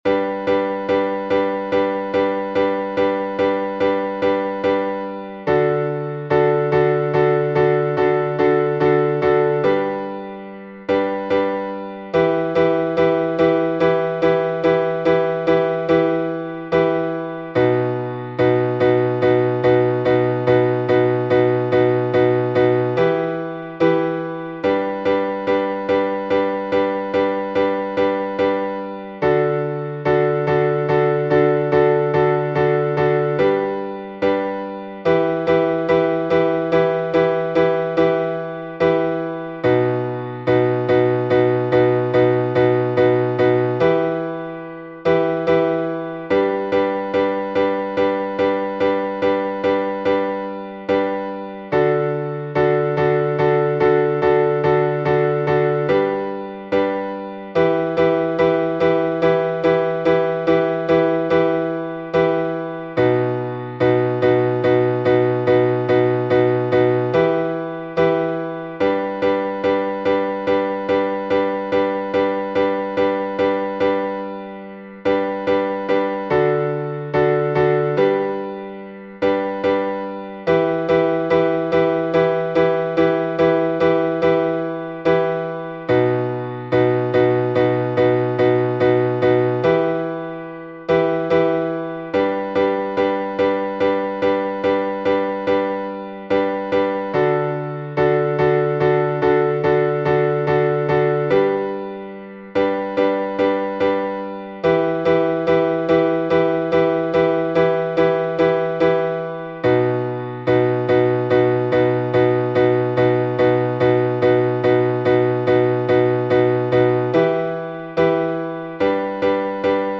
Московский напев